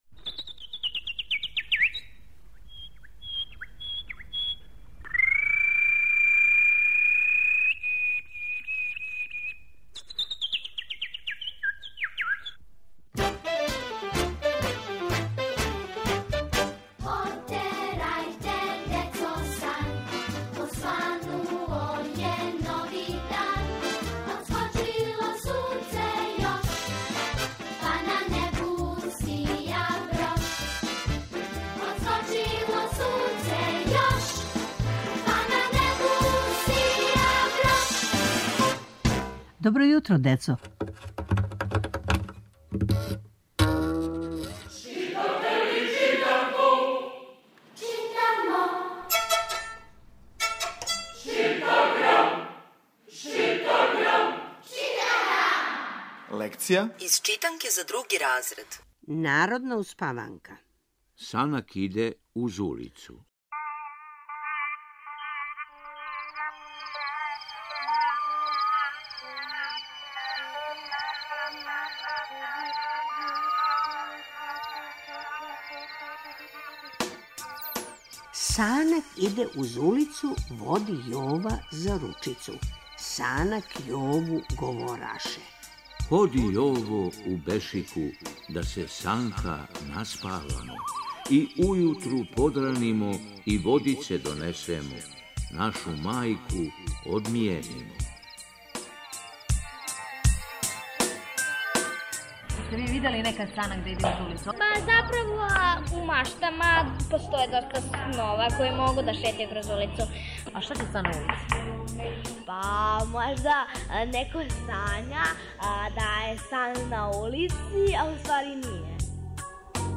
Сваког понедељка у емисији Добро јутро, децо - ЧИТАГРАМ: Читанка за слушање. Ове недеље - други разред, лекција: "Санак иде уз улицу", народна успаванка.